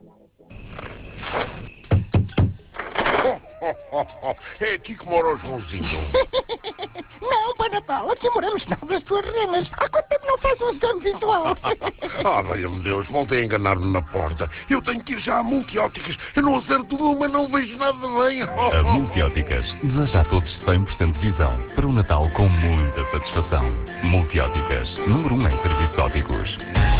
Até ao dia 9, puderam ser ouvidos 186 anúncios, cujo valor investido, a preço tabela, é de 26 127 euros.